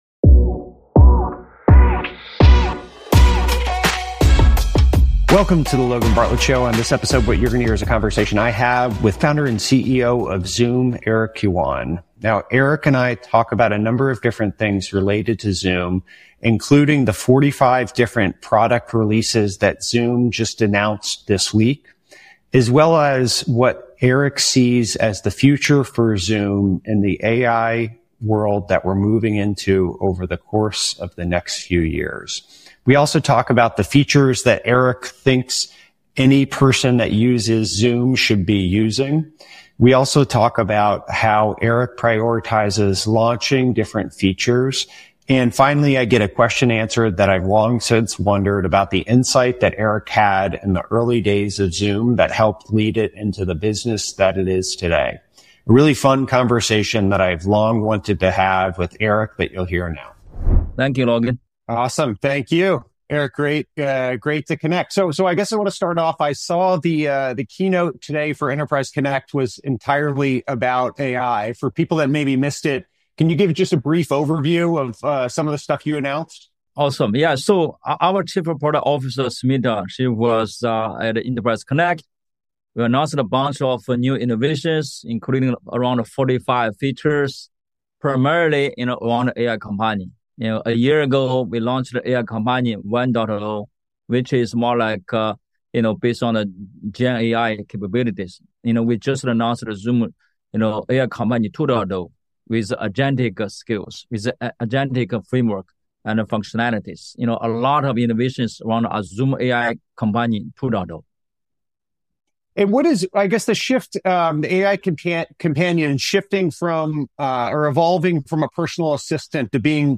After unveiling 45 new product announcements this week, Zoom CEO Eric Yuan joined the show to share how he’s thinking about the future of work.
2025 auto_awesome In this captivating conversation, Eric Yuan, Co-founder and CEO of Zoom, shares his insights on the future of work and AI. He discusses the recent launch of 45 new AI features that will reshape user experiences. Yuan also delves into the concept of digital twins, aimed at making virtual meetings feel more personal and engaging.